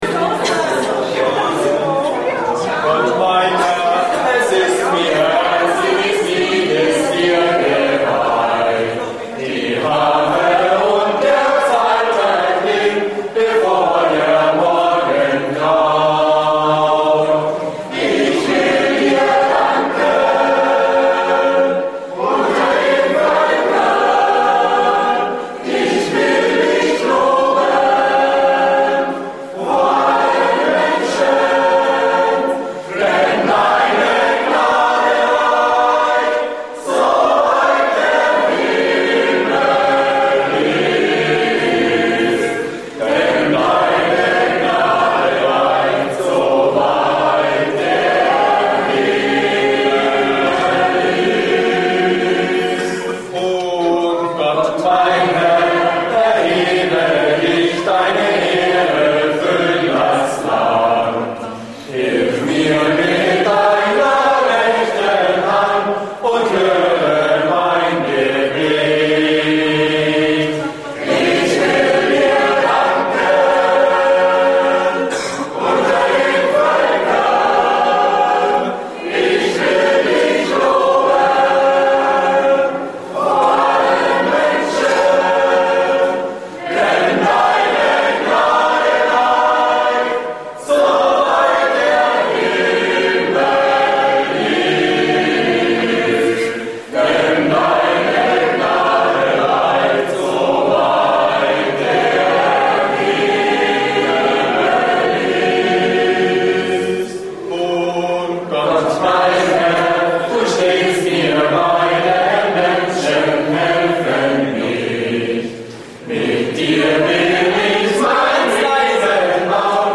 Im Archiv der Osterbegegnungen haben wir ein paar JA-Klassiker gefunden.
Herzliche Einladung zum Mitsingen und Mitspielen mit den Jugendlichen der Jungen Aktion hier.